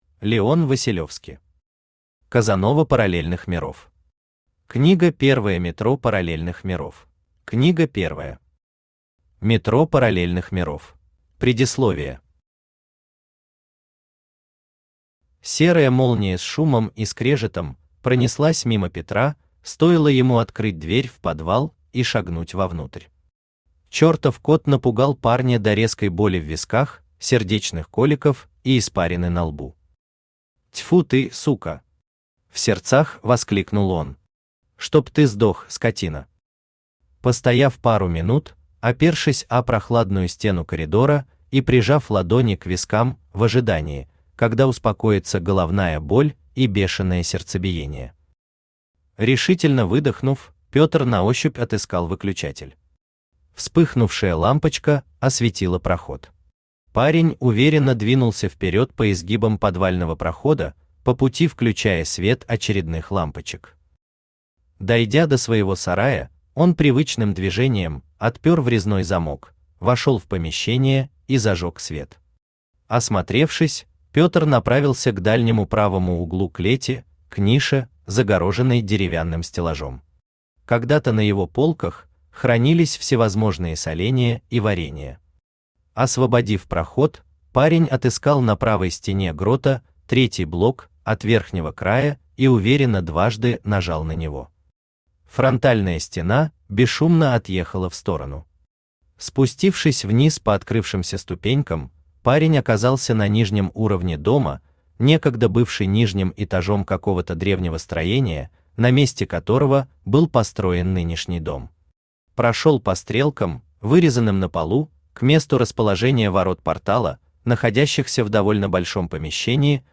Аудиокнига Метро параллельных миров. Книга первая | Библиотека аудиокниг
Книга первая Автор Леон Василевски Читает аудиокнигу Авточтец ЛитРес.